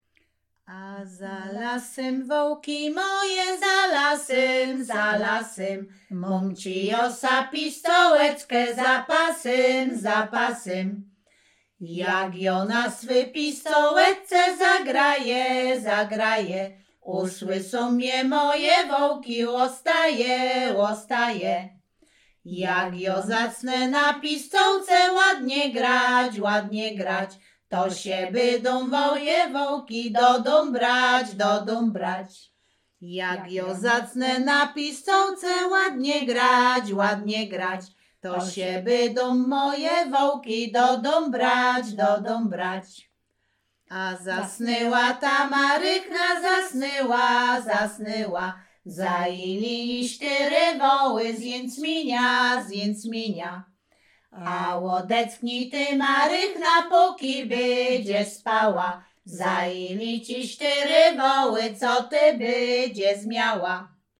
Śpiewaczki z Chojnego
województwo łódzkie, powiat sieradzki, gmina Sieradz, wieś Chojne
liryczne pasterskie